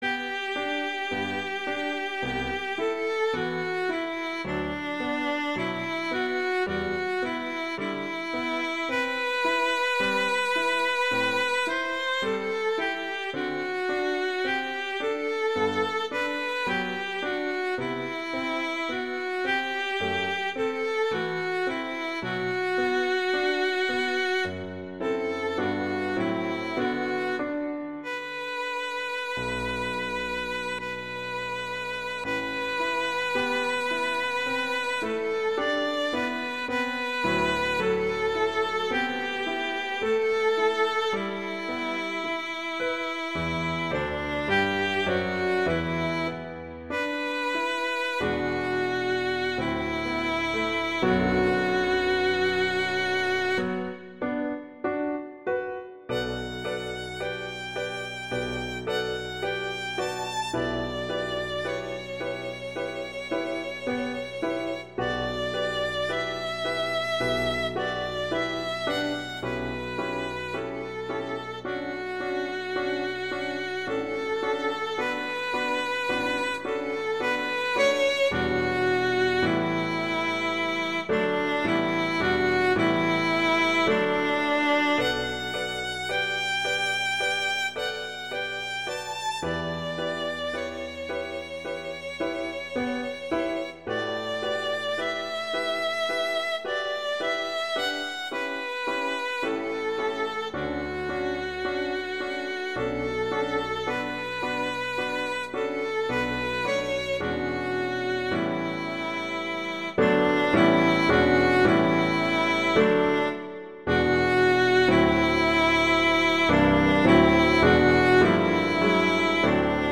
classical, french
G major
♩=54 BPM
Lovely haunting melody that is easy to get to grips with.